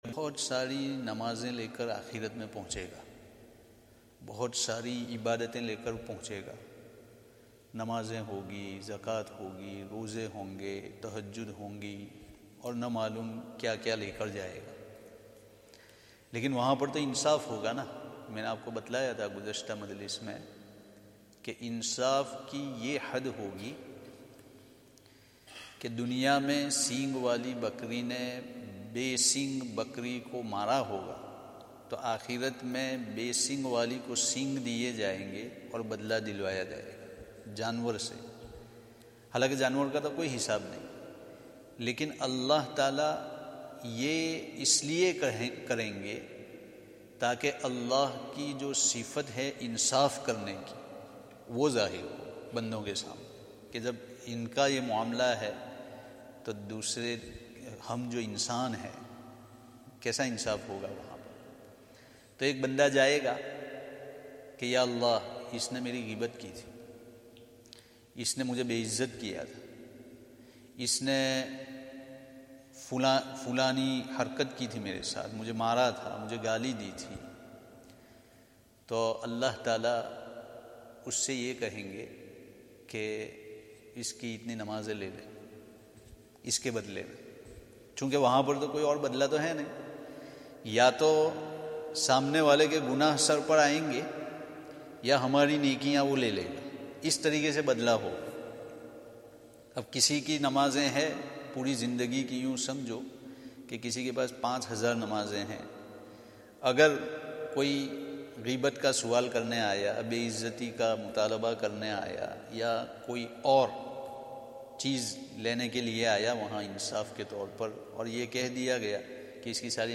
Zakariyya Jaam'e Masjid, Bolton